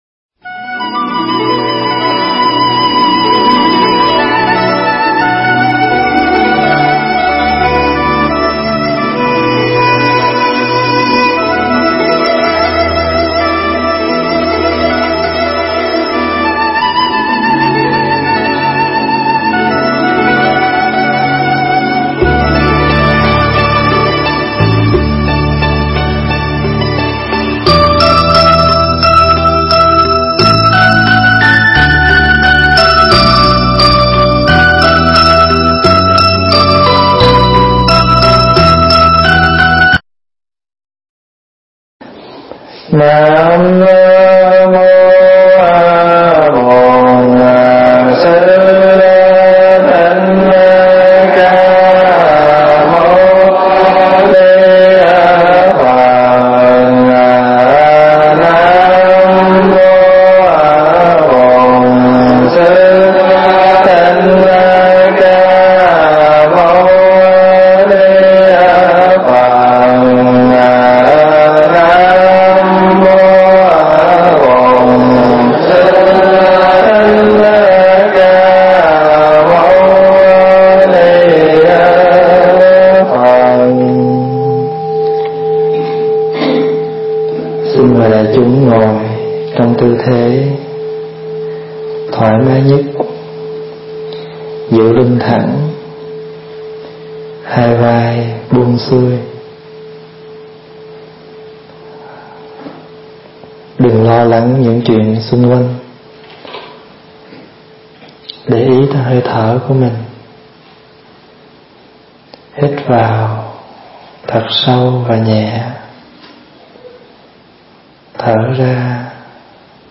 thuyết giảng tại Tu Viện Tây Thiên, Westlock County, AB, Canada